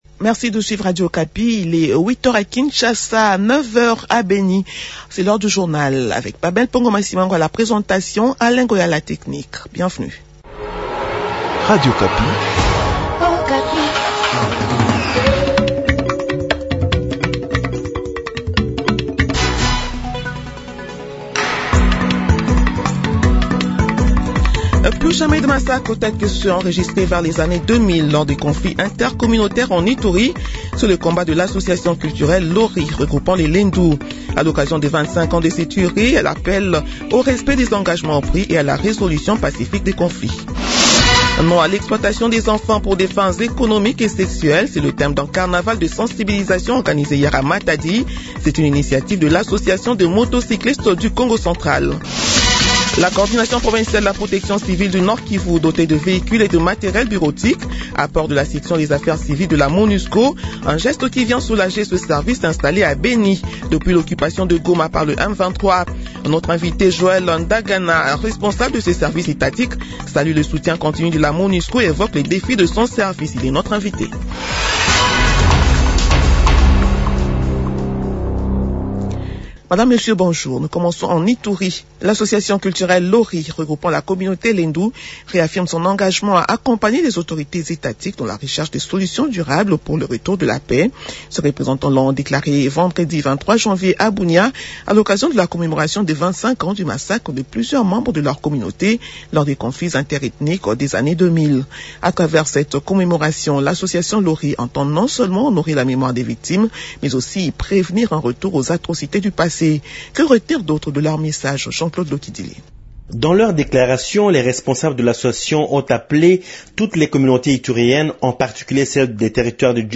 Journal de 7 heures de ce dimanche 25 janvier 2026